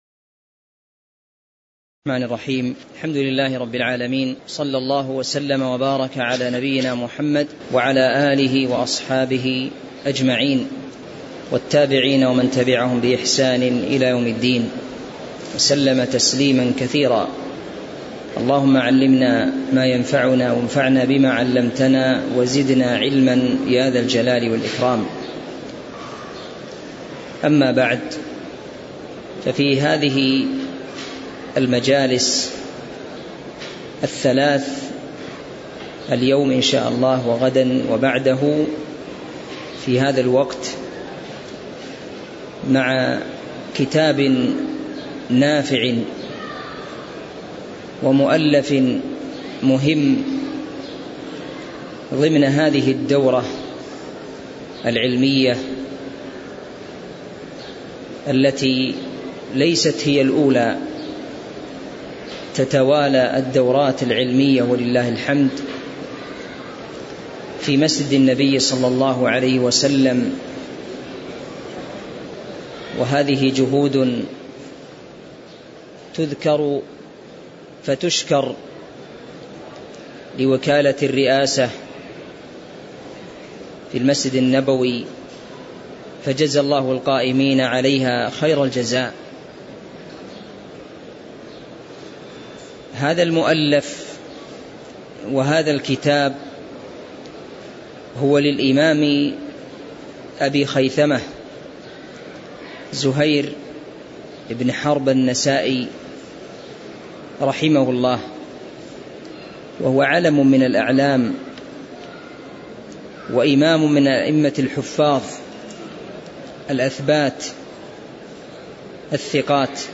تاريخ النشر ١٣ شعبان ١٤٤٤ هـ المكان: المسجد النبوي الشيخ